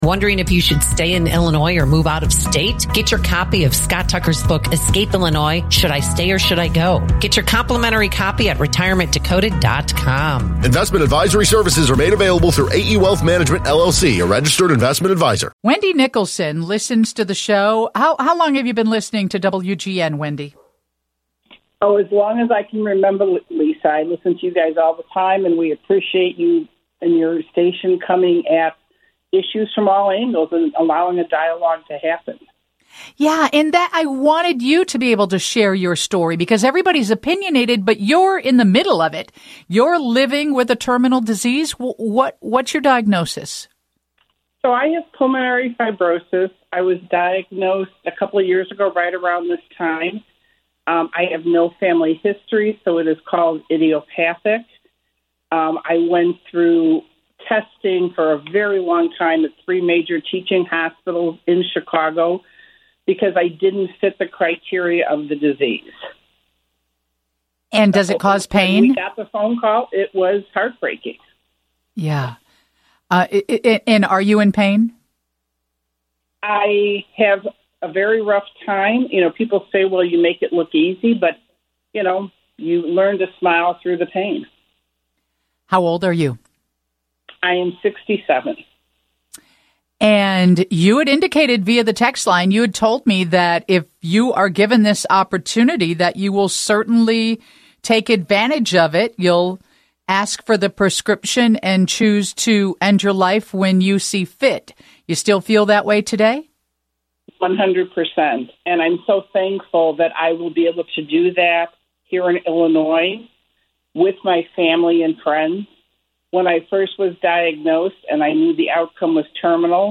She answers questions from listeners concerning her condition and perspective.